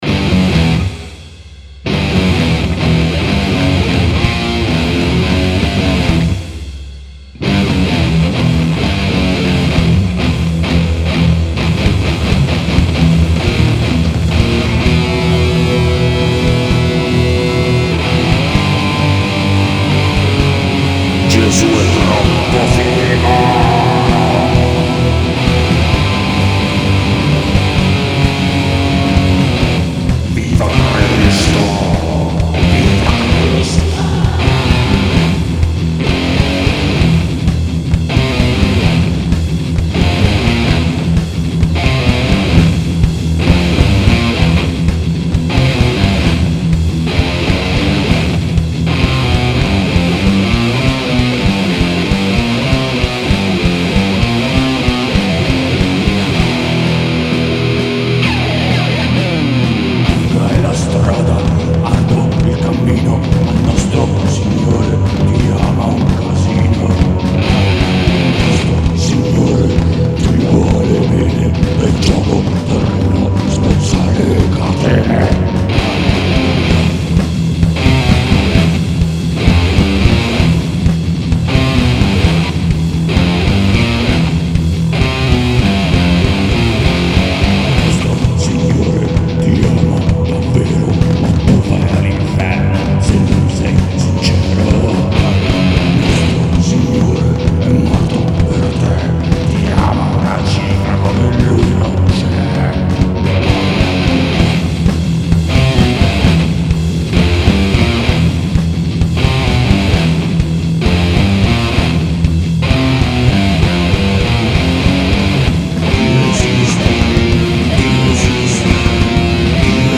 chitarrazze metal, voce metal, basso e programming